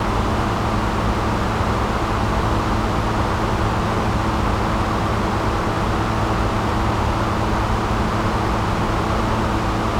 Klingt ein bisschen nach Sommer.
Aber anyways: Wir stehen inmitten einer riesigen Hühnermastanlage.
Und dieses laute Gebläse ist eine Lüftungsanlage. Diese vier oder sechs riesigen Turbinen sollen die schädlichen Gase und den Feinstaub in der Luft der kleinen gefiederten Tiere verringern und helfen im Hochsommer gegen Hitze.
HVAC.mp3